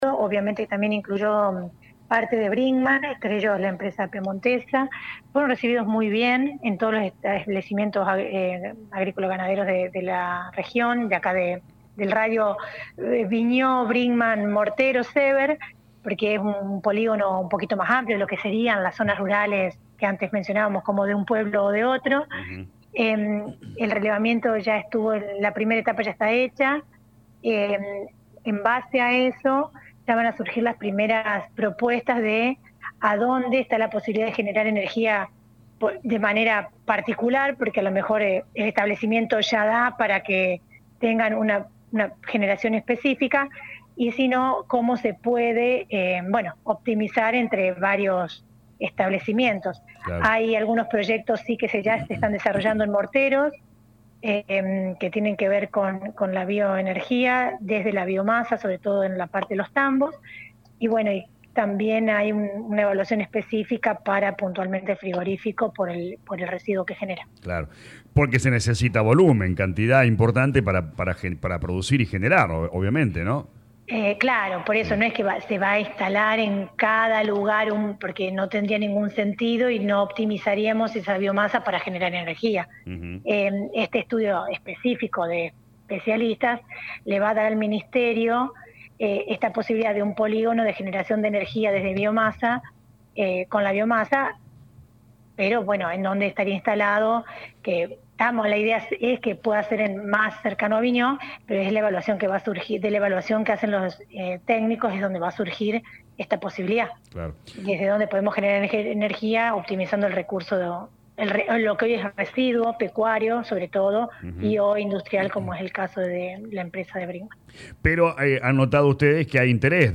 Lo explicó en diálogo con LA RADIO 102.9 FM la intendente de Colonia Vignaud Lic. Evangelina Vigna.